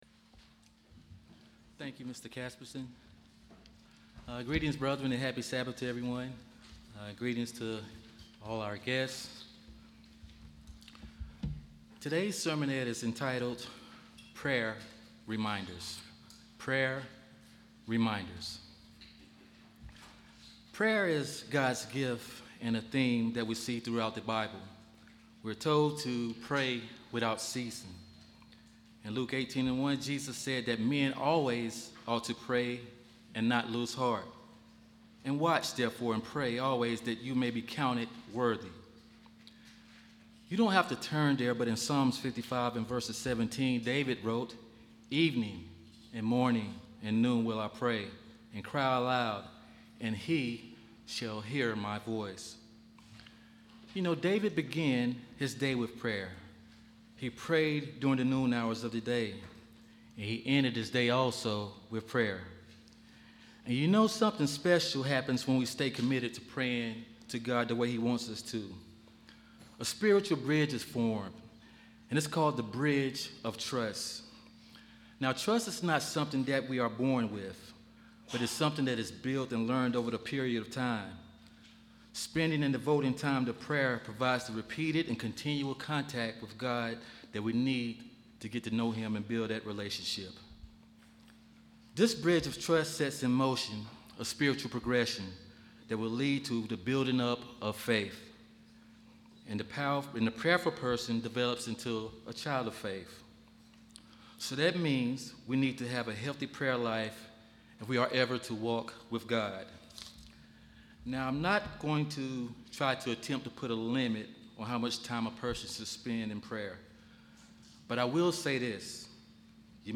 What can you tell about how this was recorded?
Given in Charlotte, NC Columbia, SC Hickory, NC